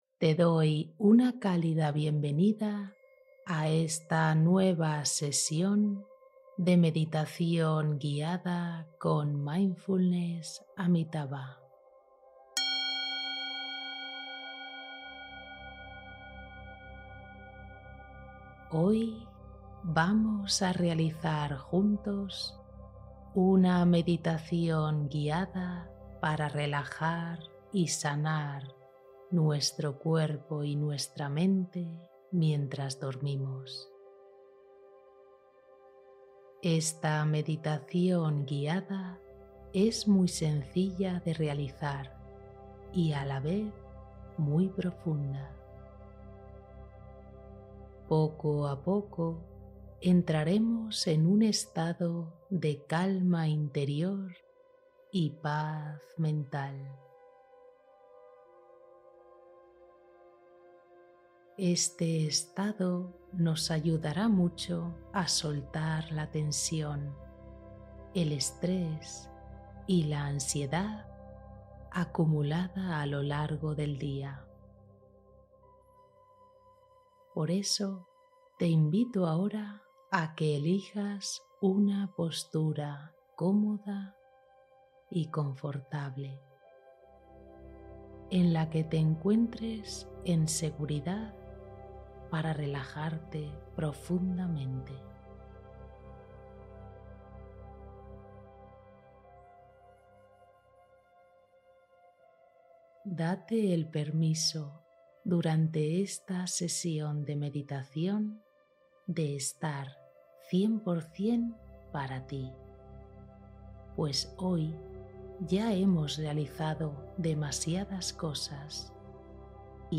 Sana cuerpo y mente mientras duermes con esta relajante meditación guiada